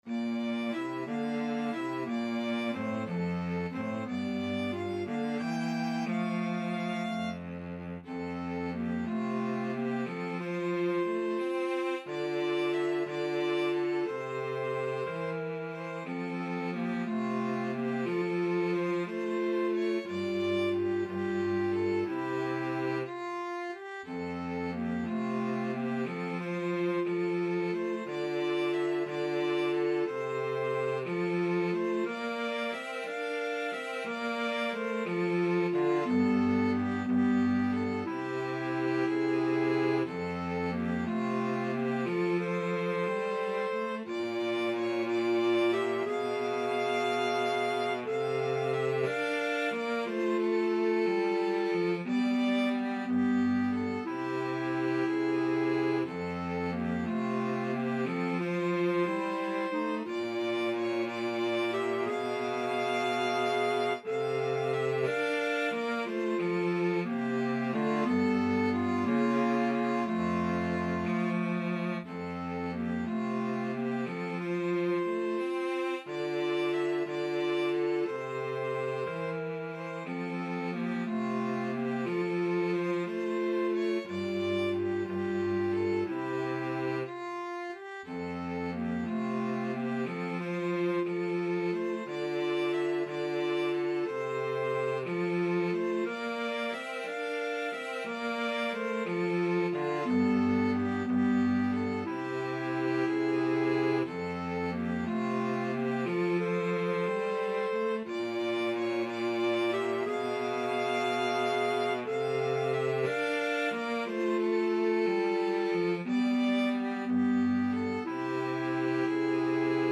Violin
Clarinet
Cello
6/8 (View more 6/8 Music)
Gently and with expression . = c. 60